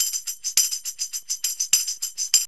TAMB LP 104.wav